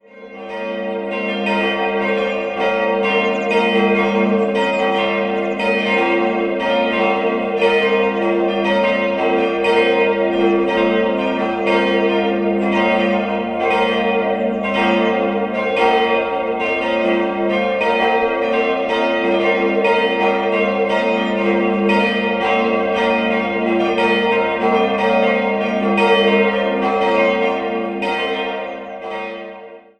Jahrhundert zurück. 4-stimmiges Geläut: g'-b'-c''-d'' Die große Glocke wurde 1480, vermutlich in Nürnberg gegossen. Die zweitgrößte wurde 1956, die drittgrößte 1990, jeweils von Bachert gegossen.